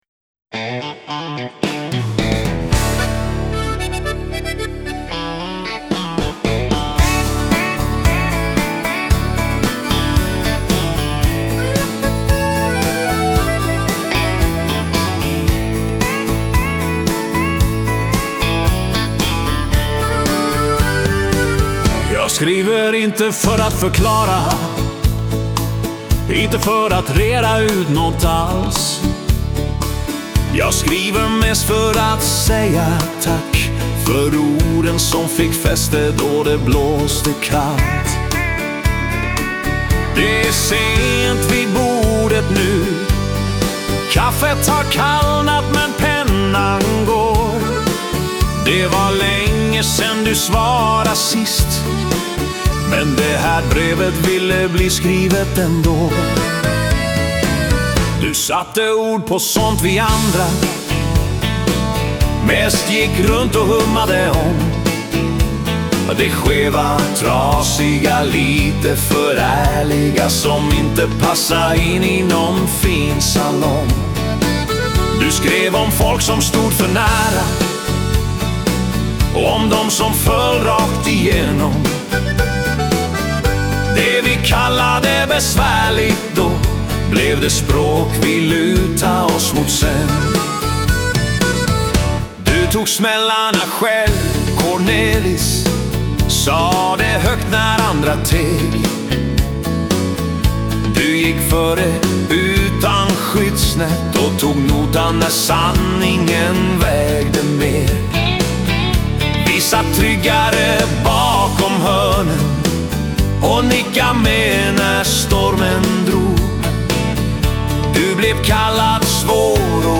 Dansbandets värme och visans allvar möts i samma rum.